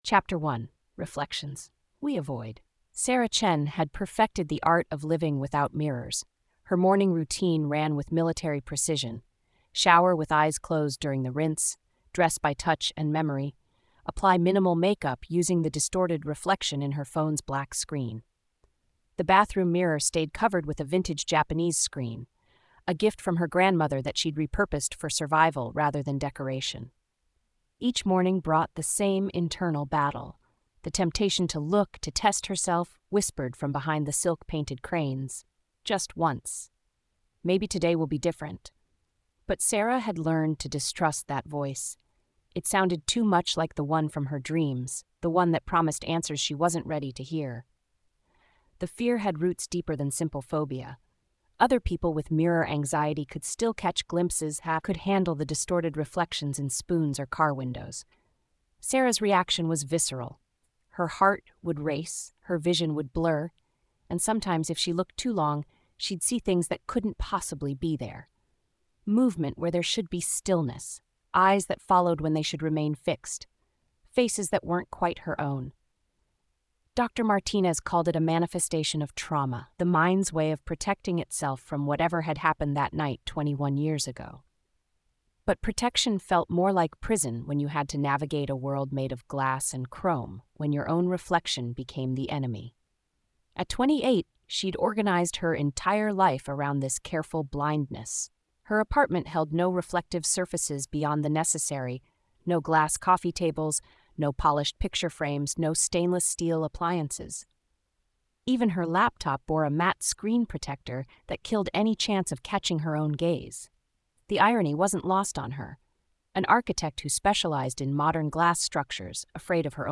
Listen to the first chapter narrated with professional voice synthesis